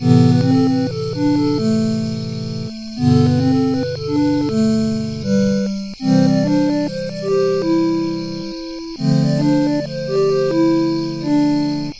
night music